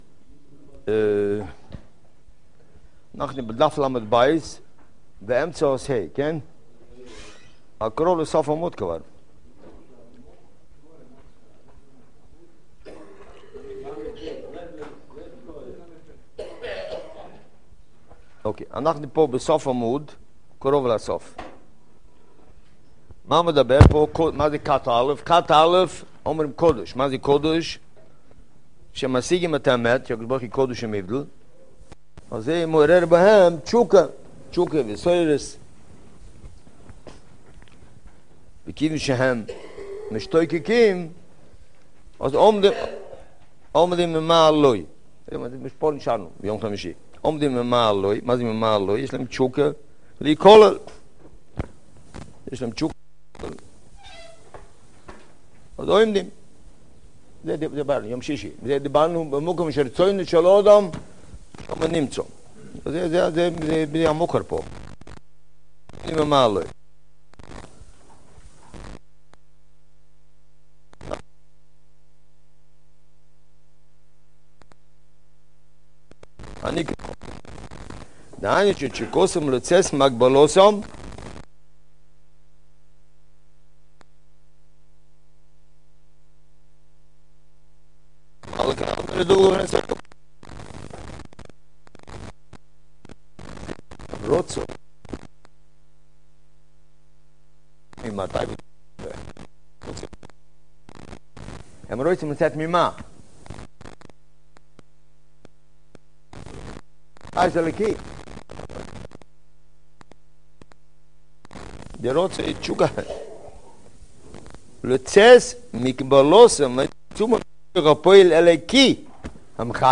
שיעור יומי